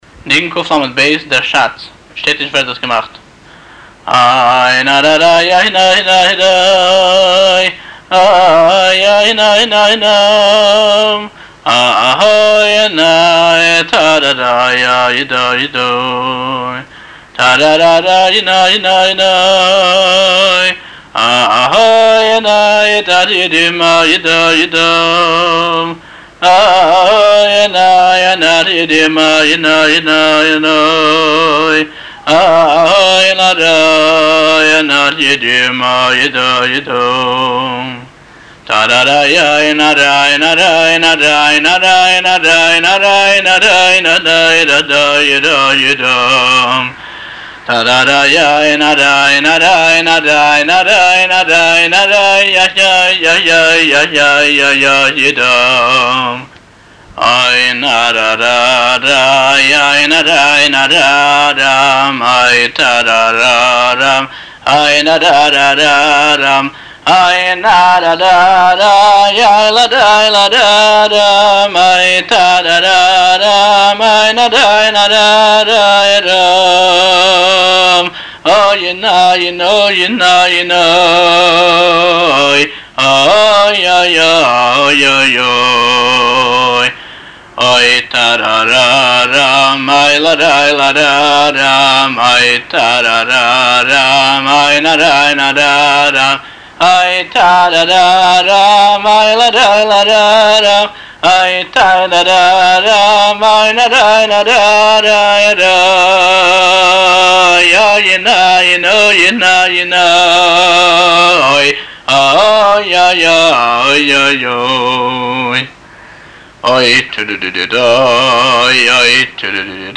הבעל-מנגן